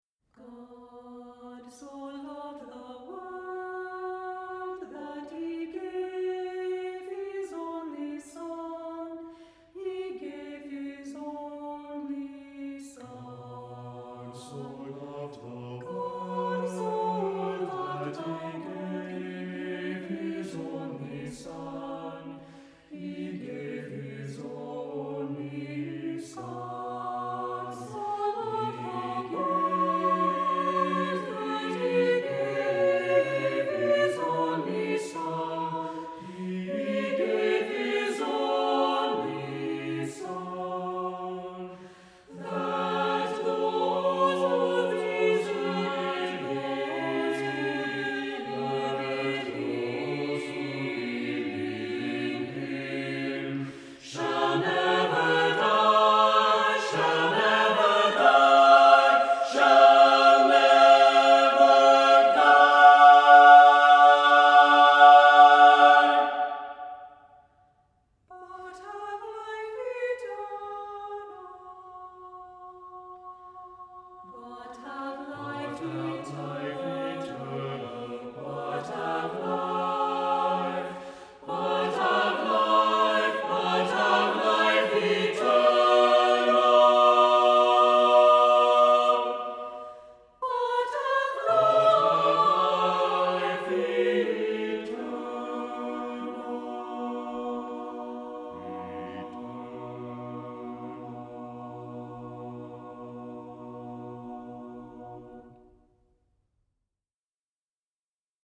Accompaniment:      A Cappella
Music Category:      Christian